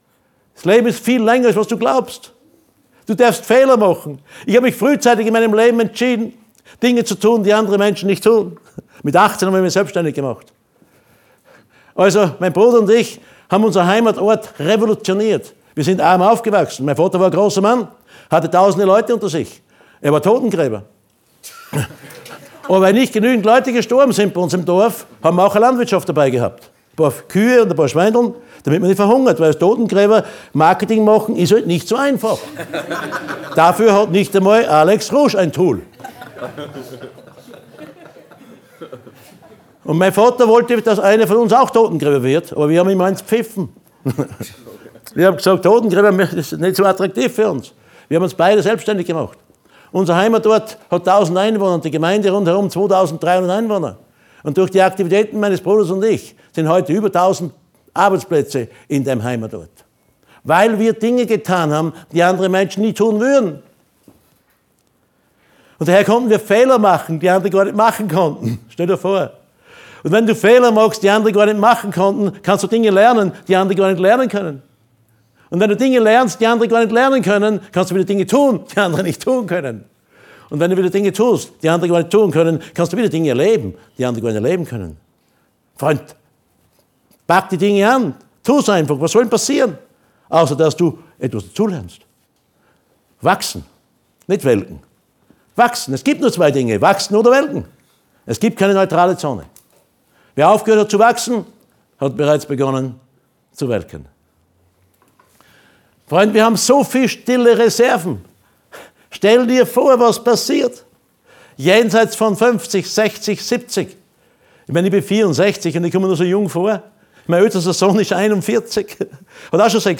LIVE-Mitschnitt Teil 03